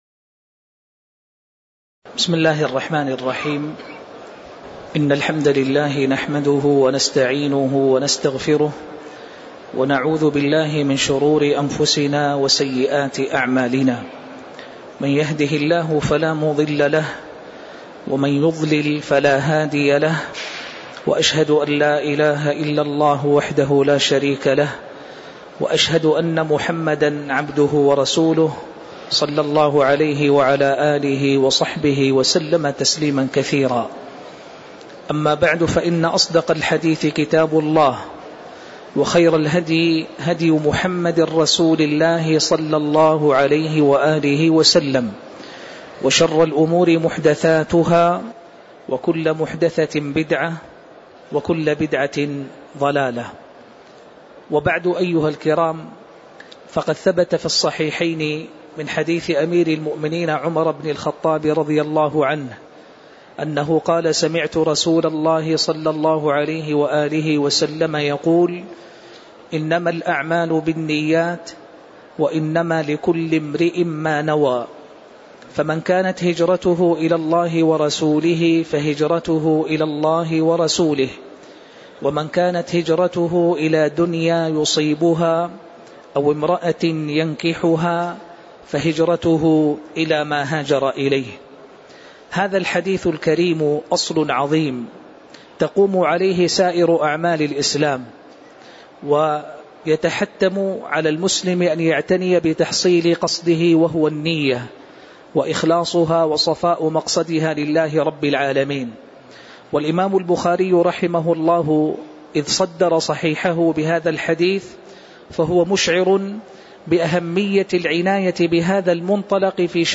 تاريخ النشر ٣ جمادى الآخرة ١٤٣٧ هـ المكان: المسجد النبوي الشيخ